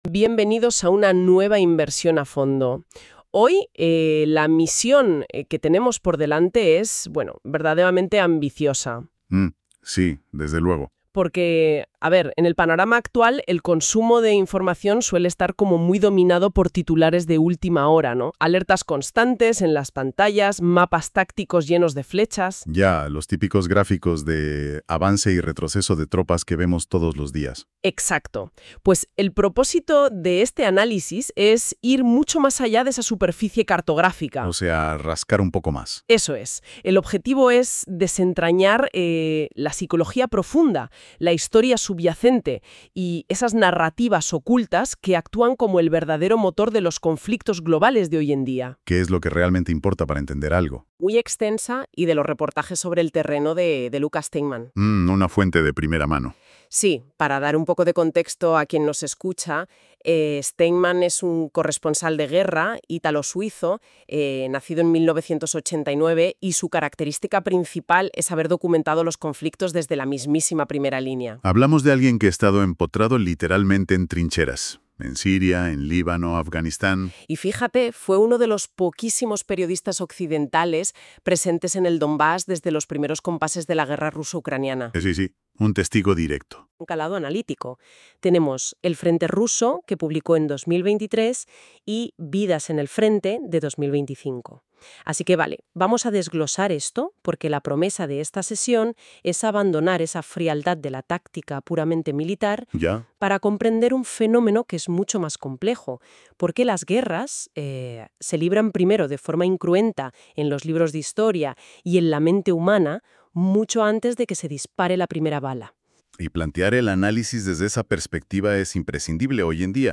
La entrevista al periodista y corresponsal de guerra